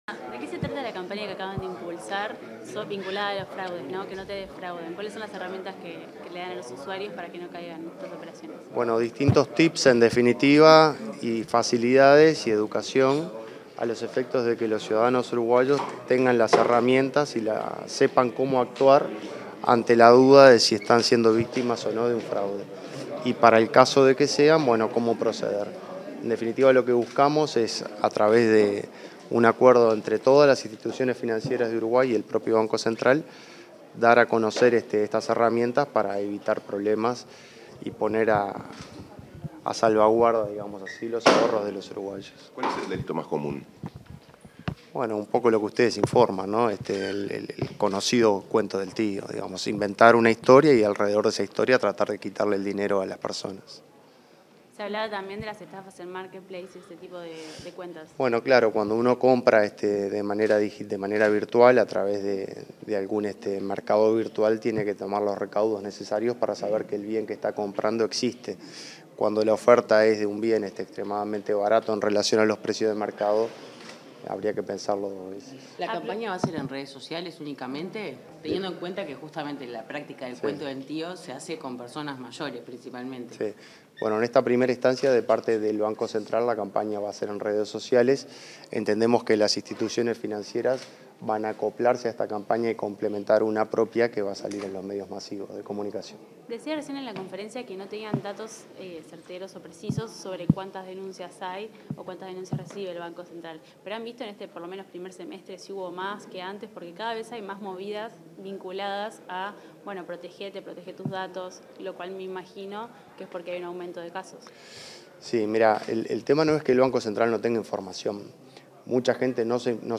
Declaraciones del presidente del BCU, Washington Ribeiro
Declaraciones del presidente del BCU, Washington Ribeiro 23/09/2024 Compartir Facebook X Copiar enlace WhatsApp LinkedIn En el marco de la campaña educativa “Que no te defrauden”, para la prevención de delitos y fraudes contra los usuarios del sistema financiero, este 23 de setiembre, el presidente del Banco Central del Uruguay (BCU), Washington Ribeiro, realizó declaraciones a la prensa.